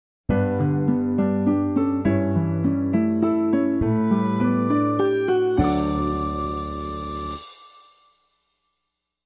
Мелодия восхода Солнца